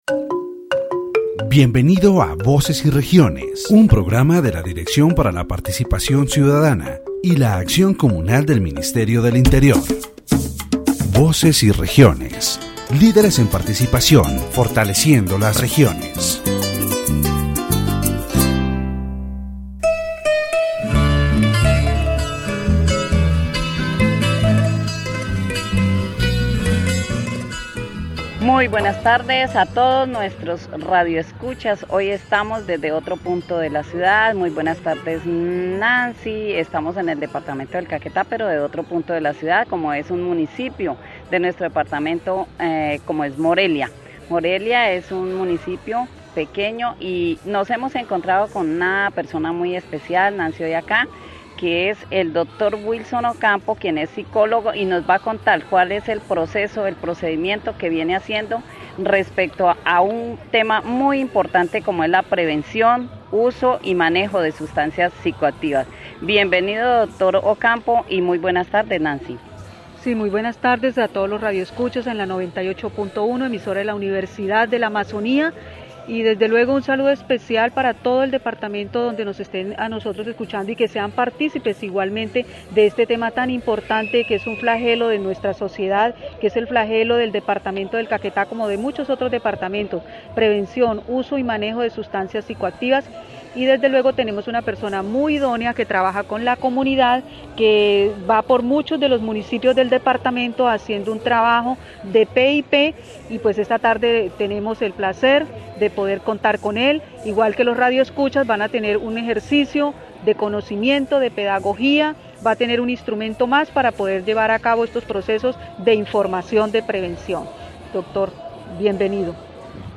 The radio program "Voces y Regiones" of the Ministry of the Interior is broadcast from Morelia, Caquetá, on station 98.1. In this episode, the topic of prevention, use and management of psychoactive substances is addressed.